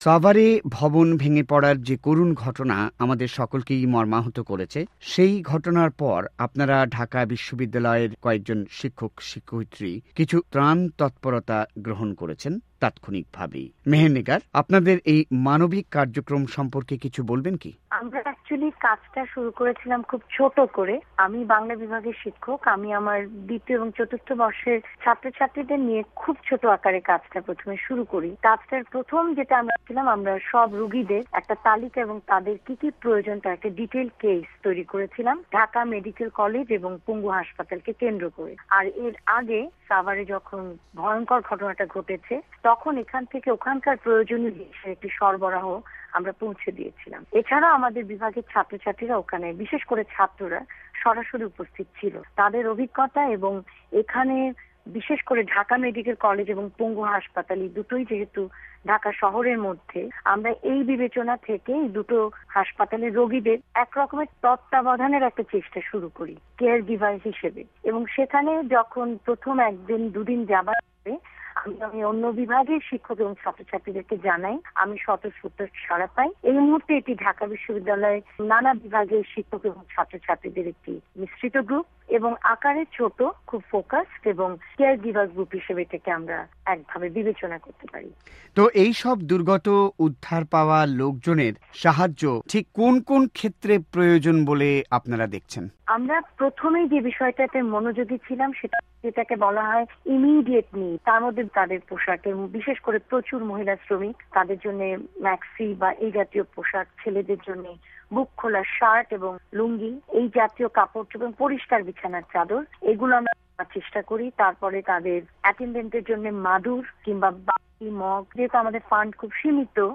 একান্ত সাক্ষাৎকারে